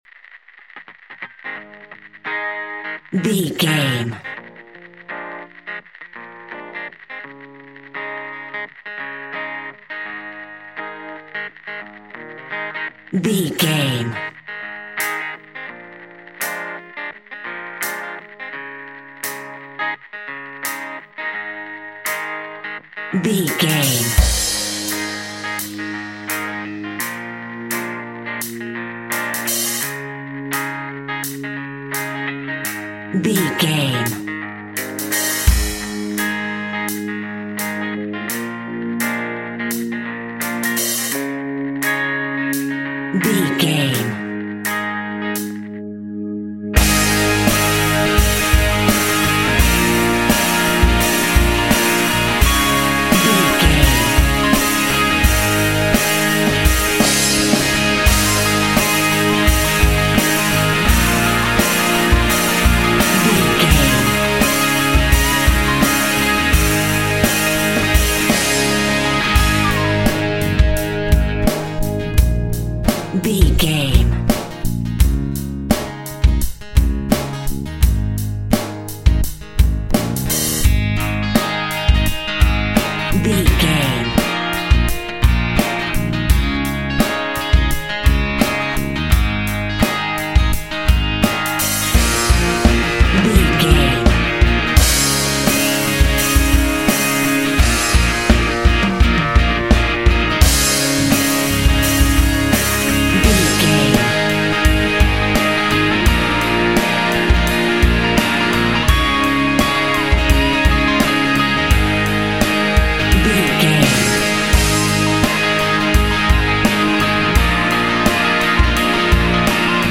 90s Brit Rock Music Theme.
Epic / Action
Fast paced
Ionian/Major
A♭
heavy rock
blues rock
distortion
brit pop music
pop rock music
drums
bass guitar
electric guitar
piano
hammond organ